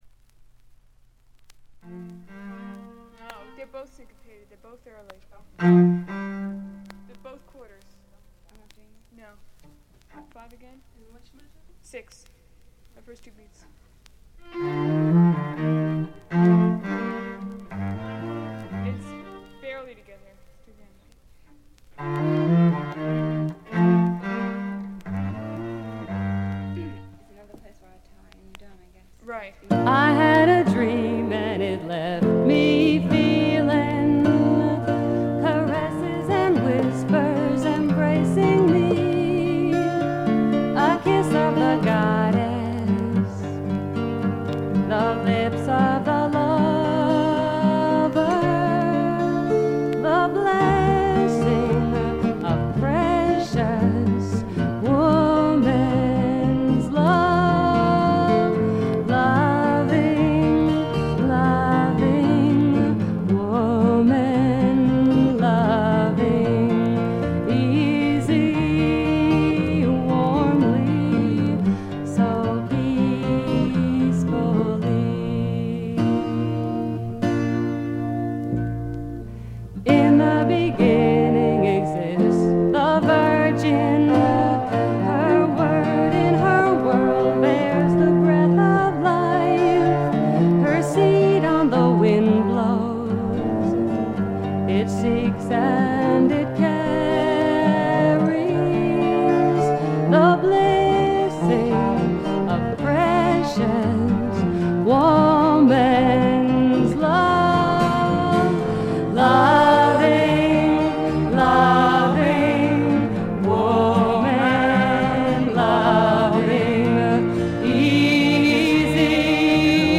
細かなバックグラウンドノイズやチリプチは多め大きめに出ますが鑑賞を妨げるほどではないと思います。
自主フォーク、サイケ・フォーク界隈でも評価の高い傑作です。
試聴曲は現品からの取り込み音源です。